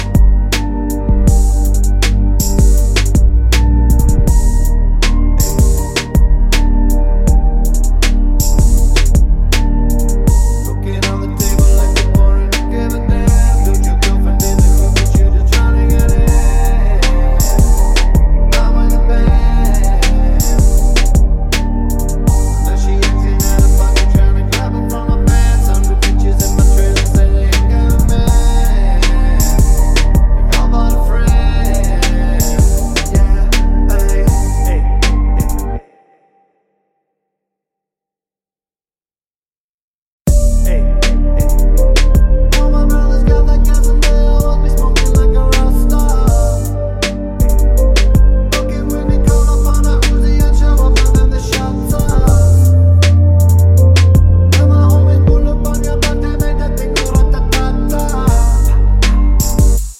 Clean Version R'n'B / Hip Hop 3:39 Buy £1.50